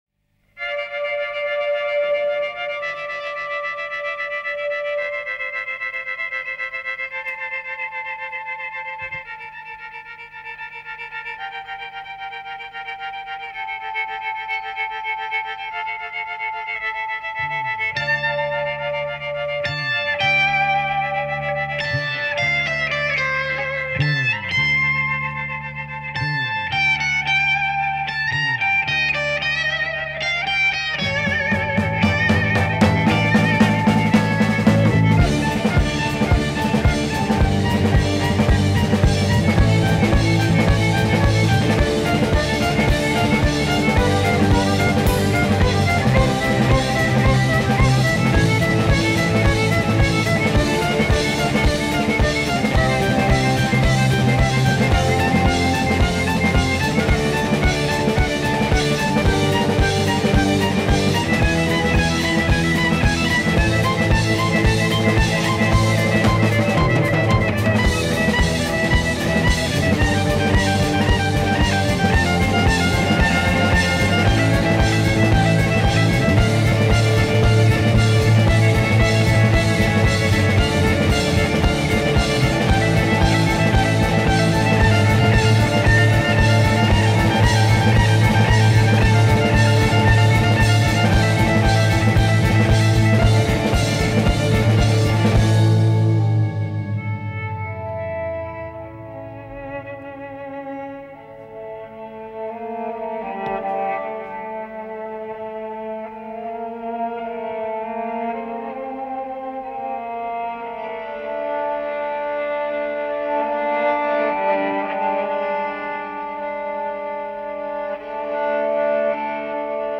relied heavily on the violin as a prominent instrument
violin
soulful voice